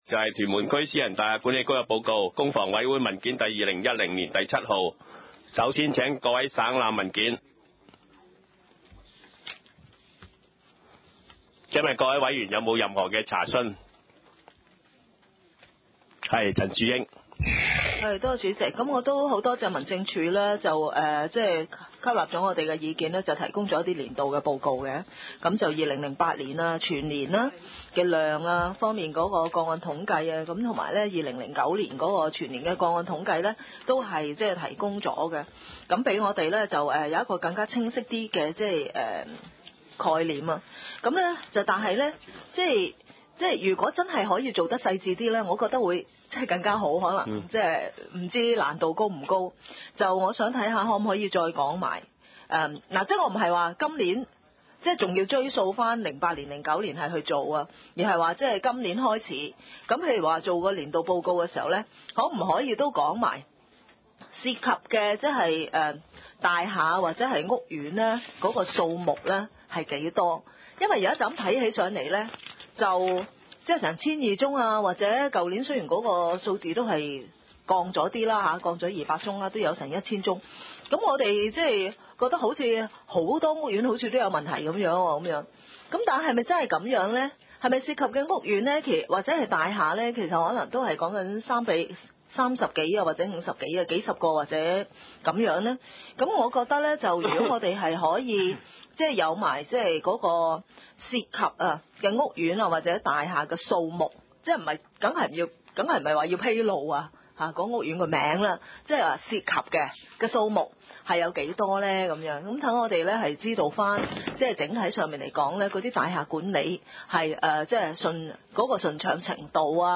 屯門區議會 2010-2011年工商業及房屋委員會 第三次會議議程 日期：2010年4月12日（星期一） 時間：上午9時30分 地點：屯門屯喜路一號 屯門政府合署三樓 屯門區議會會議室 議程 討論時間 (I) 通過會議記錄 2010-2011年工商業及房屋委員會第二次會議記錄 2:16 (II) 討論事項 要求改善屯門舊式工廈消防設施 29:13 (工房委文件2010年第5號) (III) 報告事項 1.